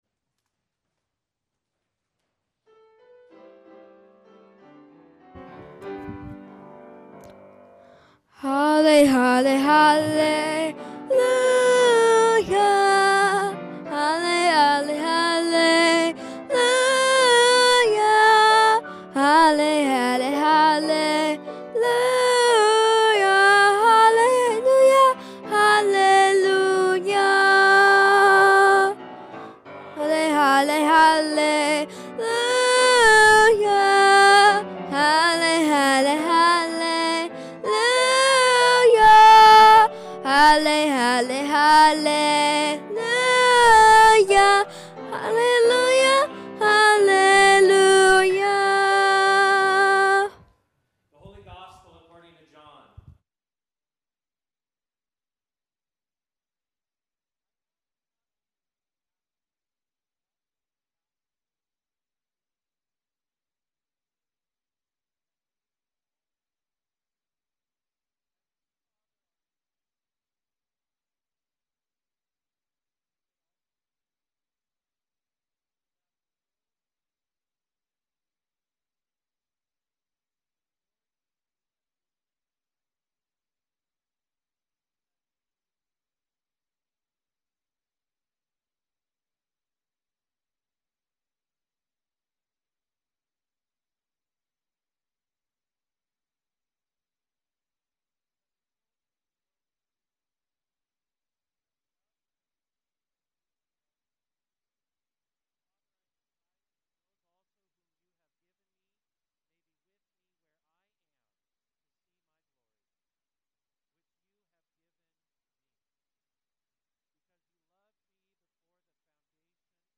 Sermon 06.01.25